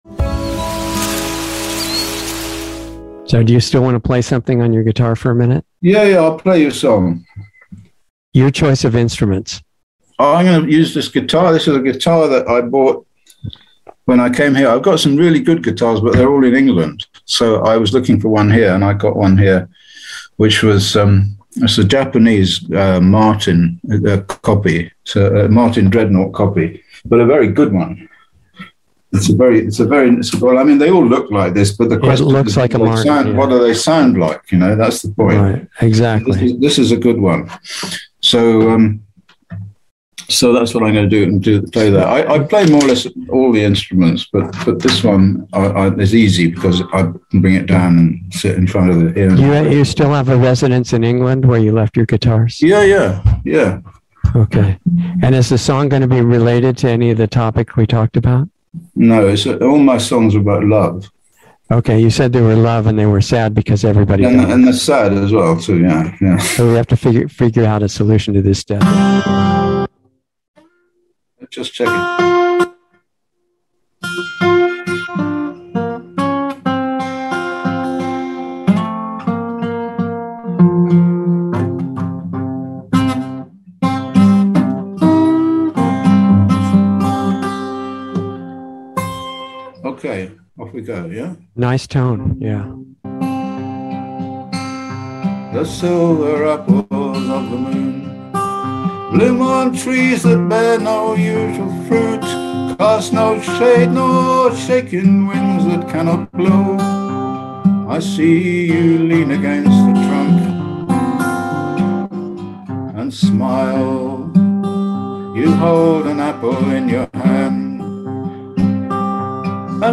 9 Songs + 1 Song Performed Live